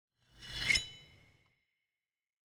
sword draw.wav